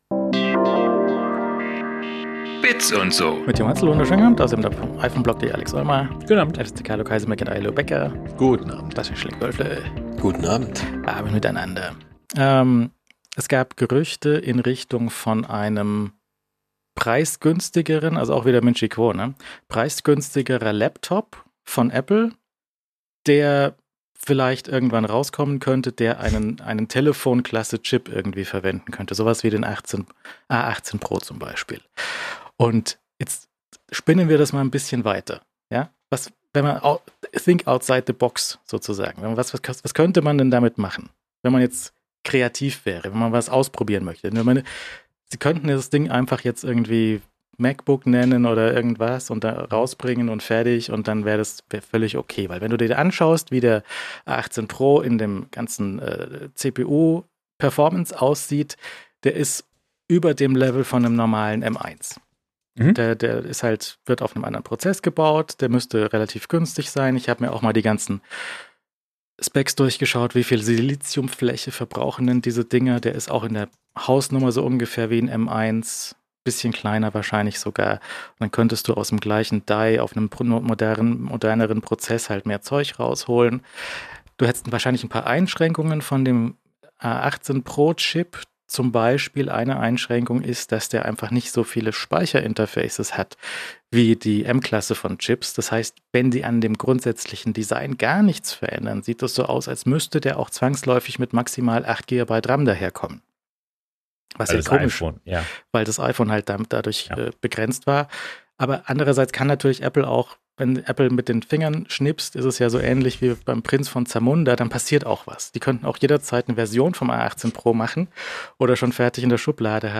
Die wöchentliche Talkrunde rund um Apple, Mac, iPod + iPhone, Gadgets und so. Fast live aus München.